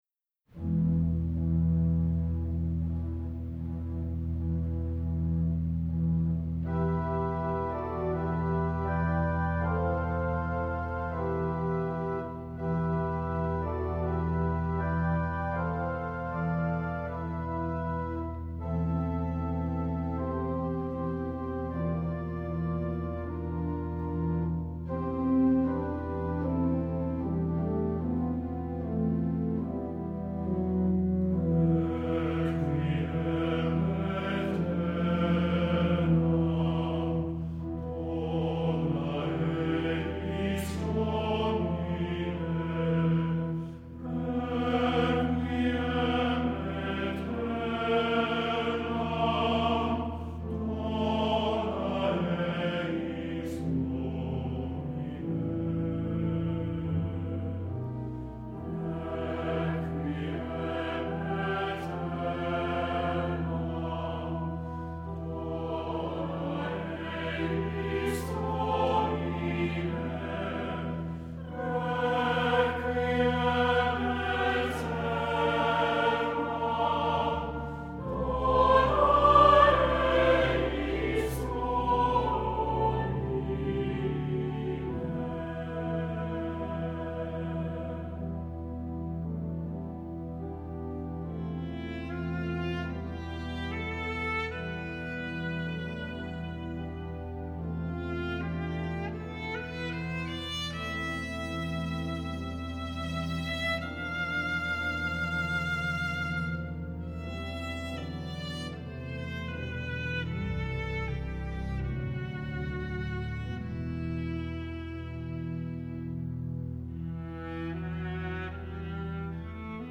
Voicing: STB, Viola or Cello, and Organ